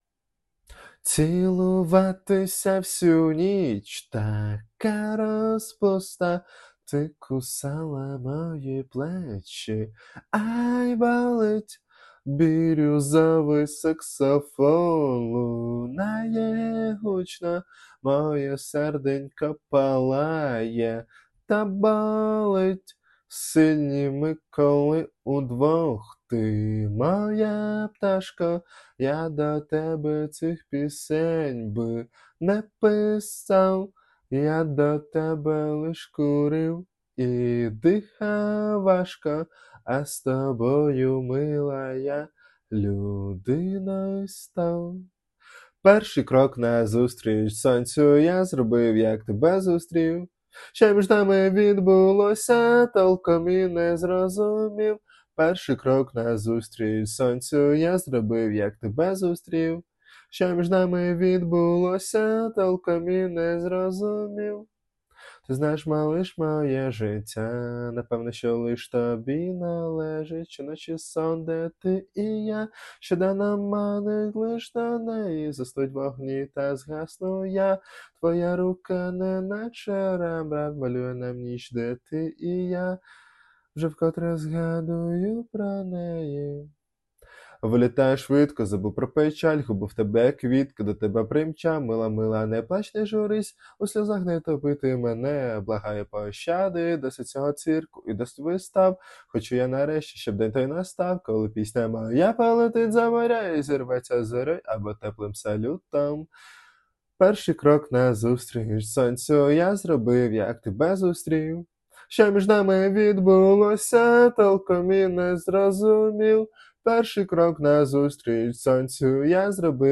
Вокал
Тенор